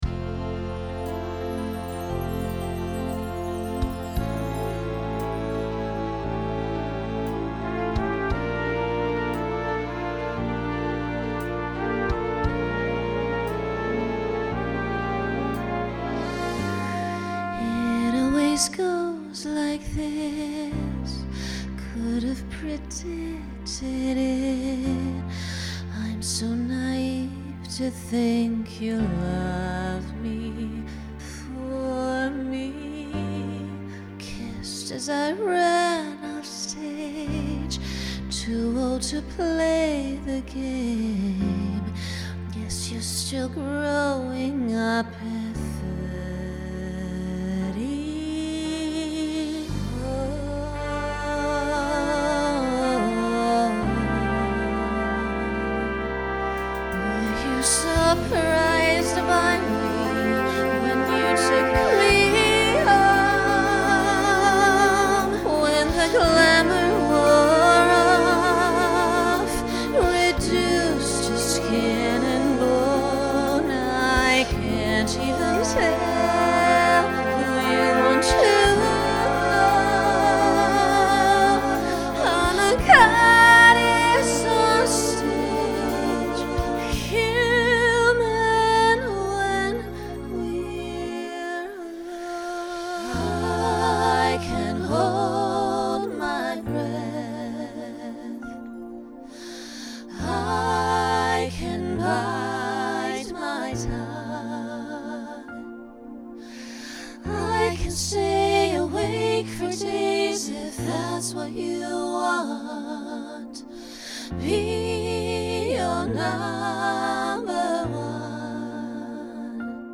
Genre Pop/Dance
Function Ballad Voicing SSA